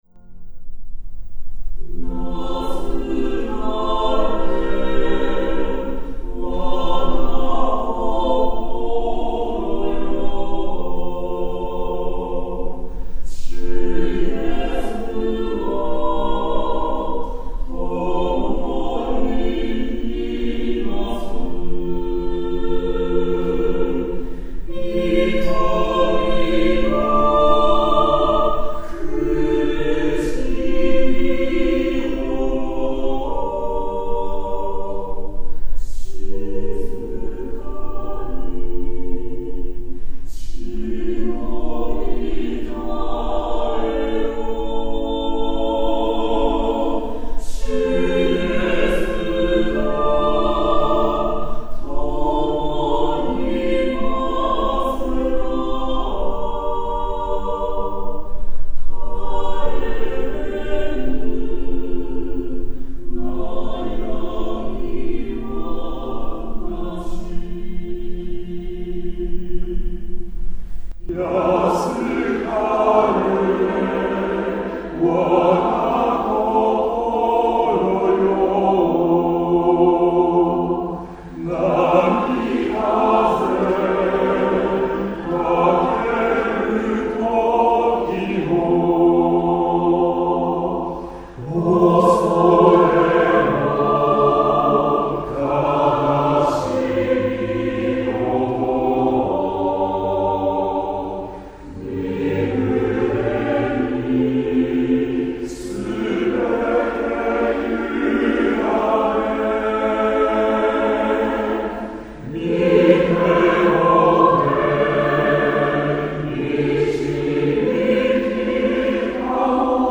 前奏付♪
練習参考編集：アカペラ重唱〜アカペラ合唱〜合唱とオルガン
(2節でバス強調)